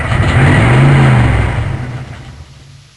vehicle_stop.wav